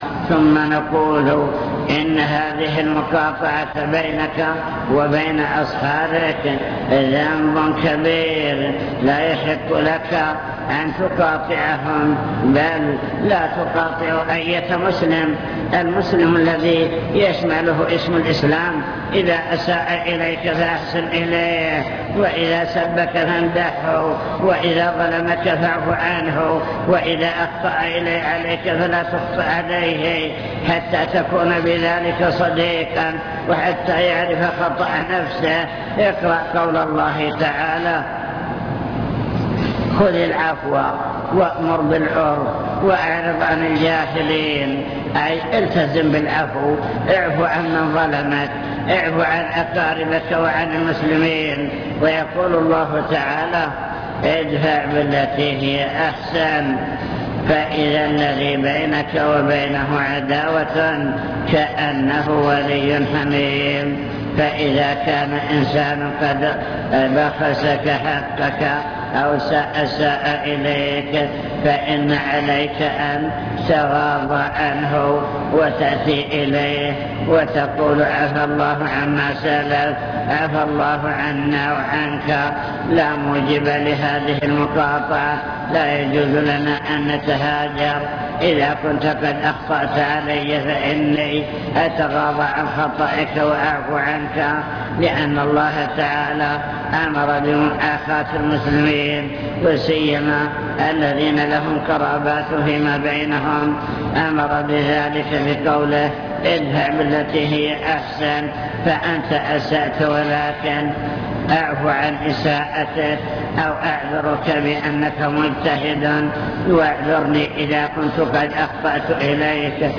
المكتبة الصوتية  تسجيلات - محاضرات ودروس  محاضرة حول توجيهات في العقيدة والأسرة